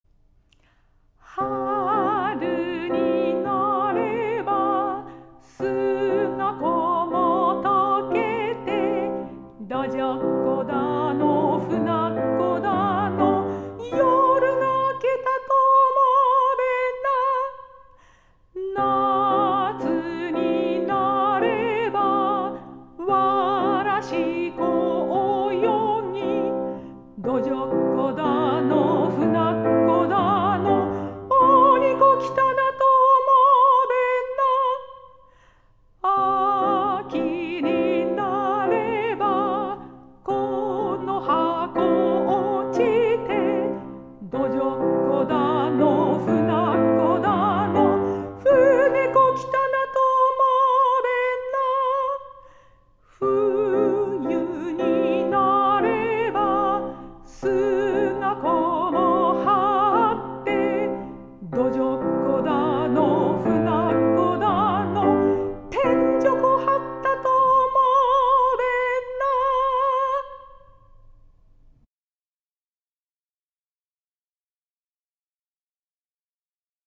メゾ・ソプラノ
ピアノ